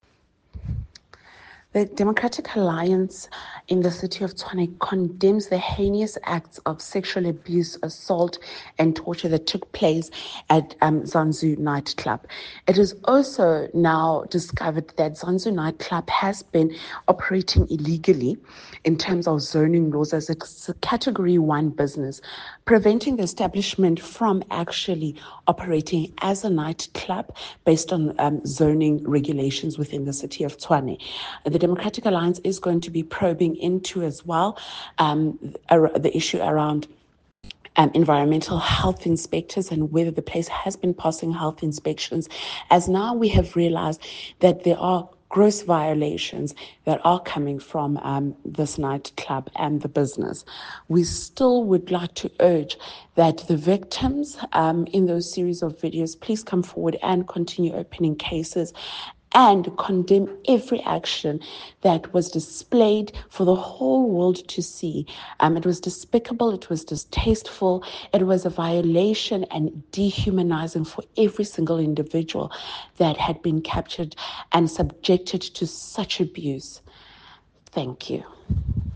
Note to Editors: Please find an English soundbite by Cllr Dikeledi Selowa